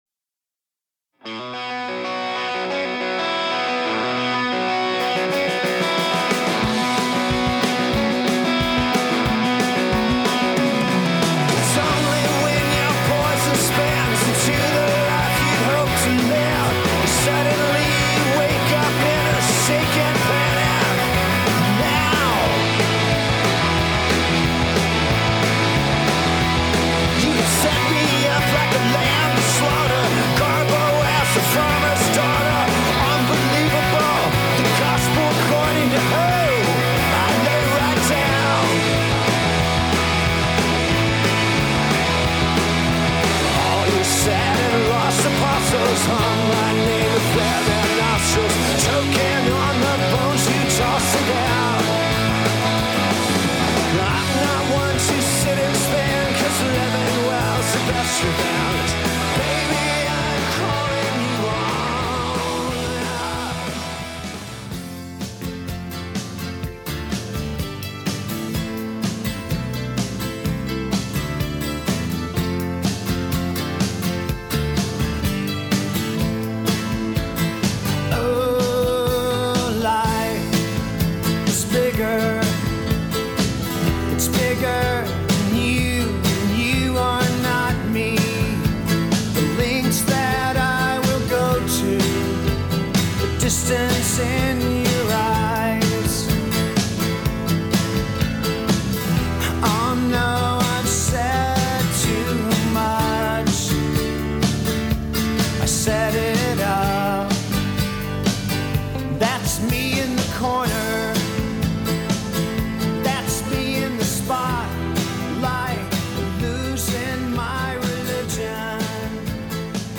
one of the most frenetic openings of any album
big, arena rock sound